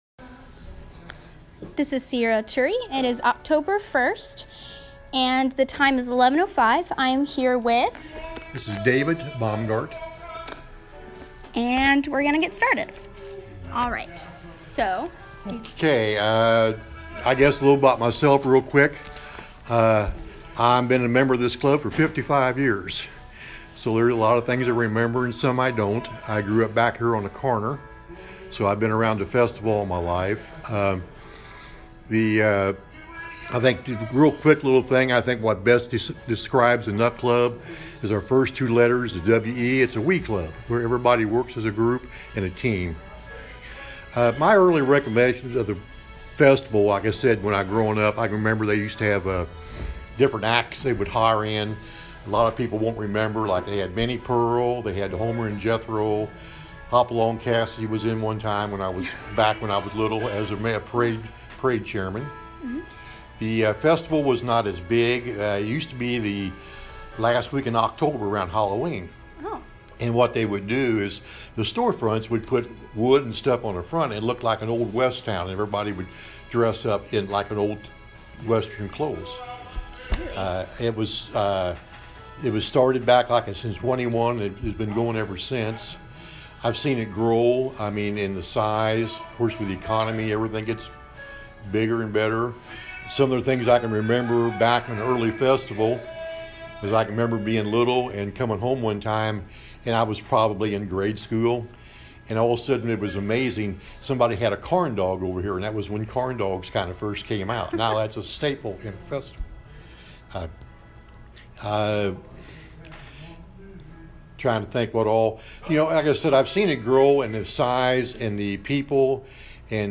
Interview
Evansville, Indiana